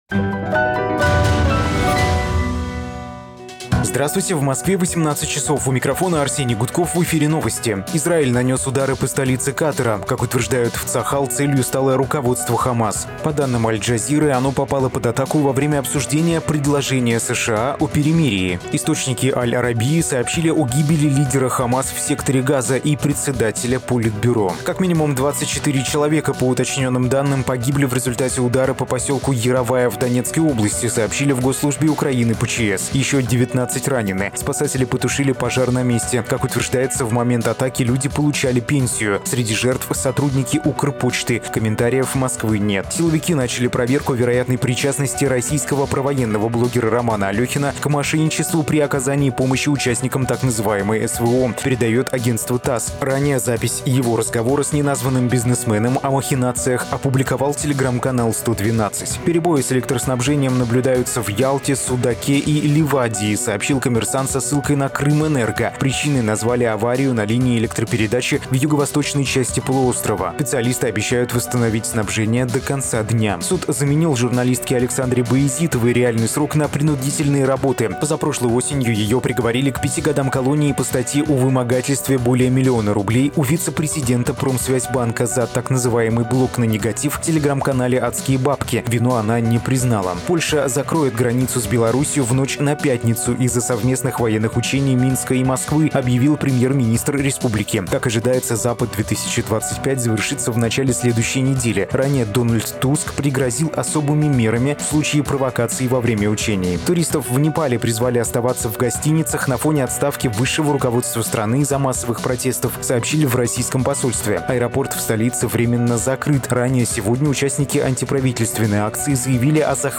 Слушайте свежий выпуск новостей «Эха»
Новости 18:00